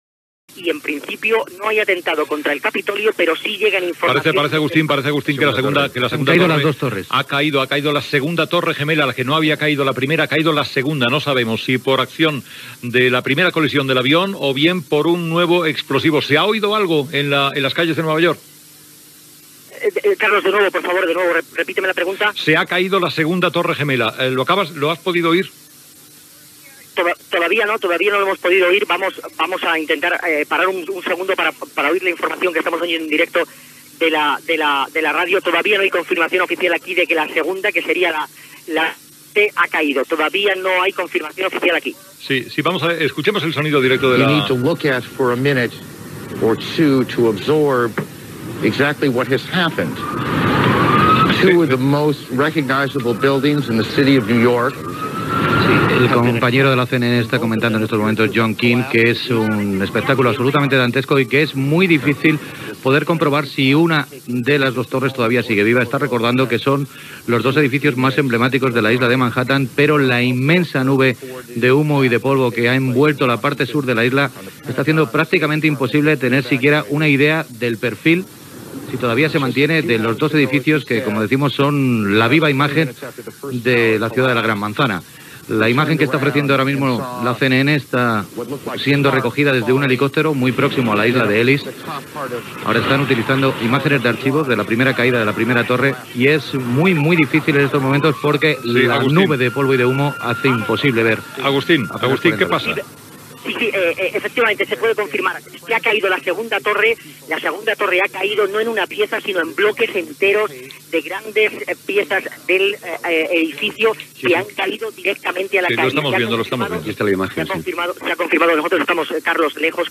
Caiguda de la segona torre bessona del World Trade Center a Nova York, amb connexió amb la transmissió de la CNN
Informatiu